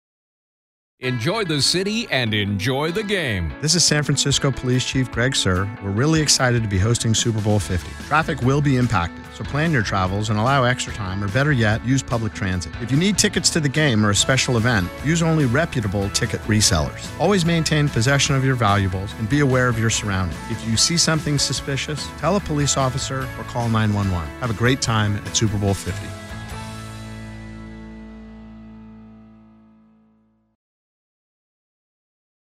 Two 30-second Super Bowl 50 safety radio spots, also featuring Chief Suhr and produced by Cumulus Broadcasting, are attached to this release.